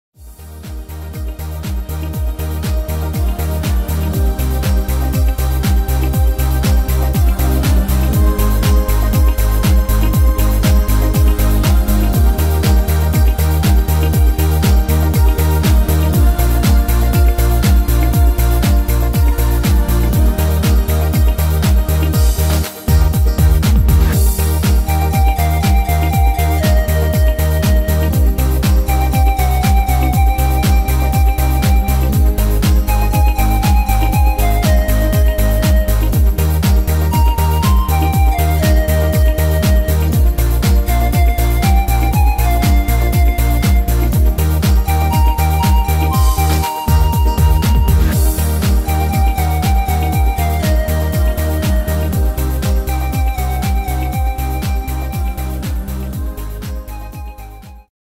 Tempo: 120 / Tonart: Eb